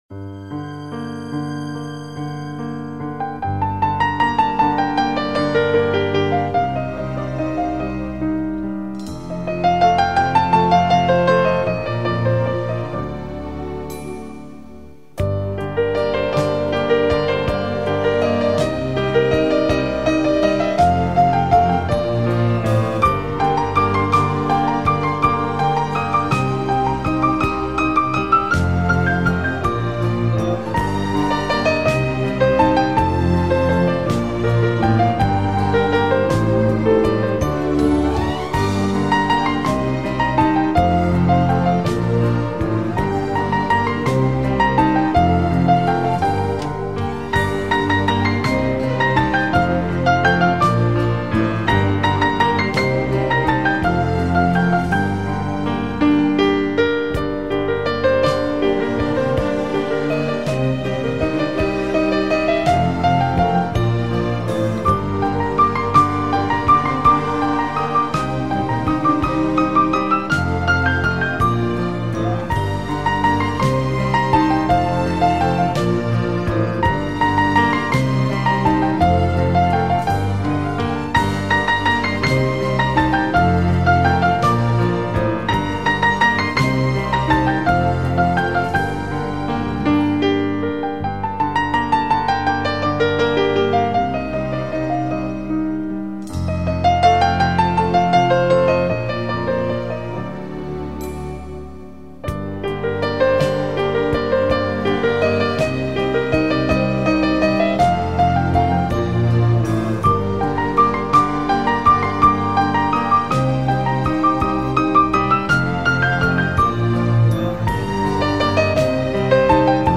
نوازنده مشهور پیانو فرانسوی است.